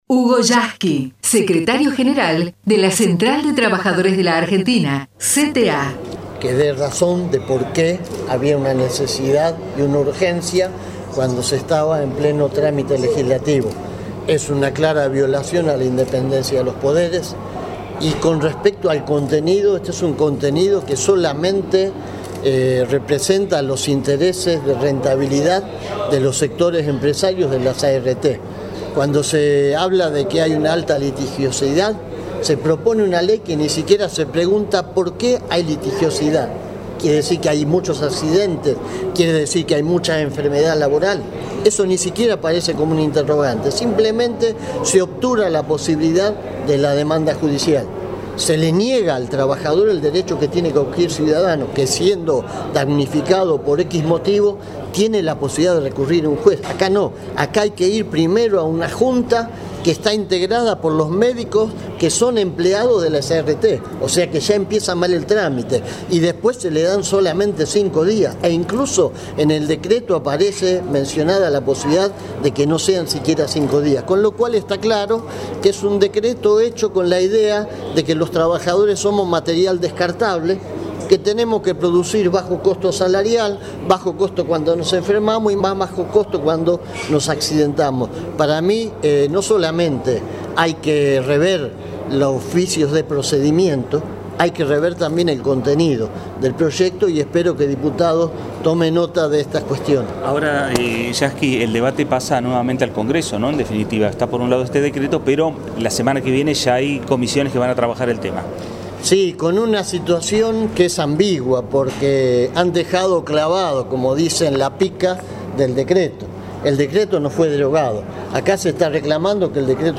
HUGO YASKY EN DIÁLOGO CON LOS MEDIOS // Debate DNU por ART
Secretario General de CTA de los Trabajadores en el Congreso de la Nación.